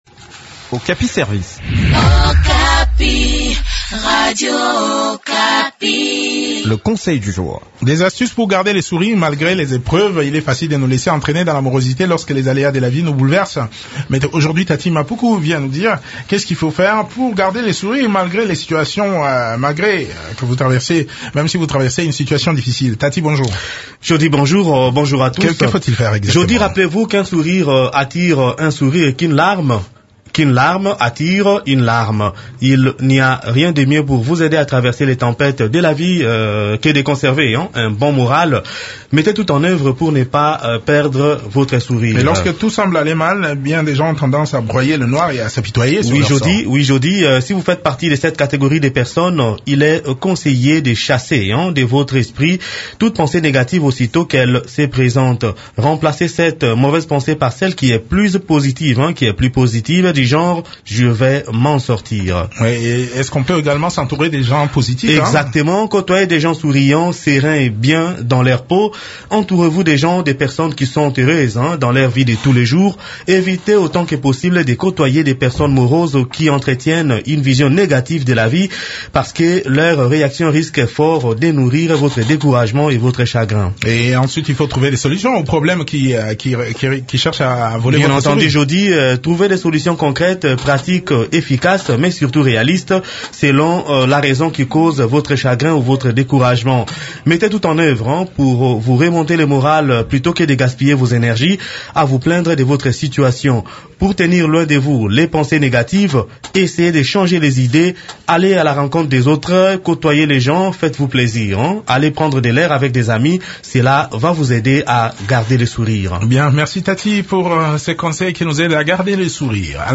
Découvrez des astuces qui peuvent vous aider à garder le sourire malgré les épreuves dans cette chronique